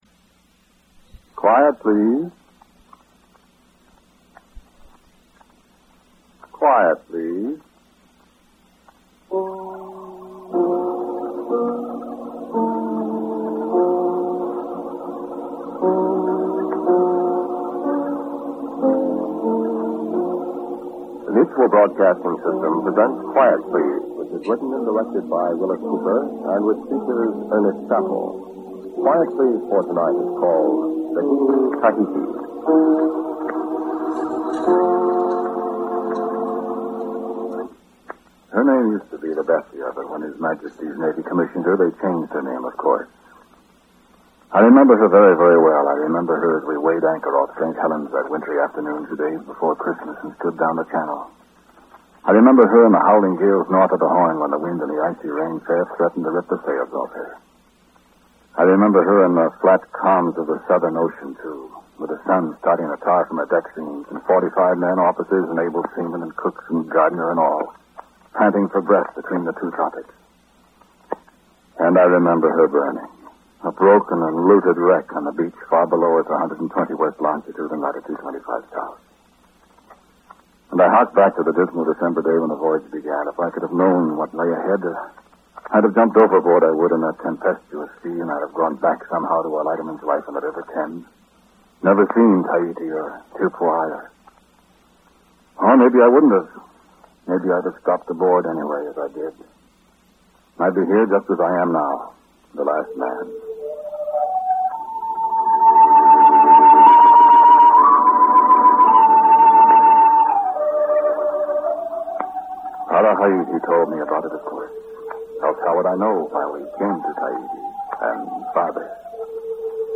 Noise-reduced version